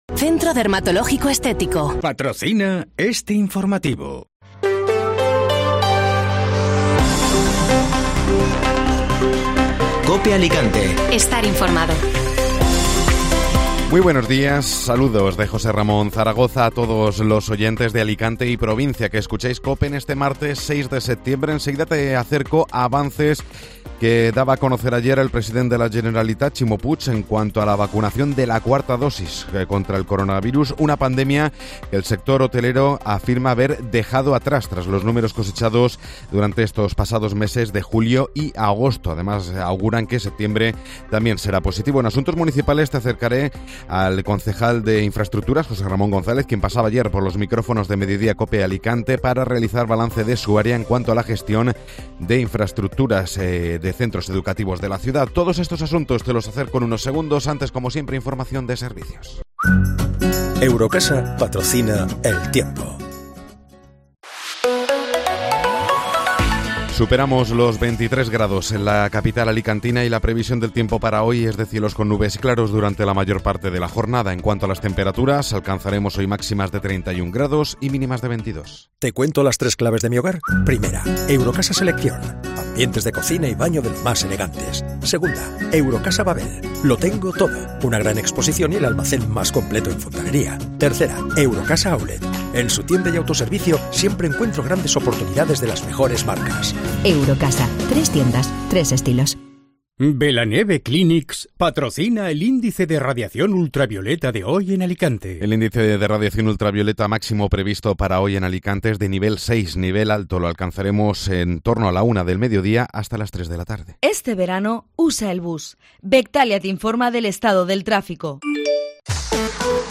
Informativo Matinal (Martes 6 de Septiembre)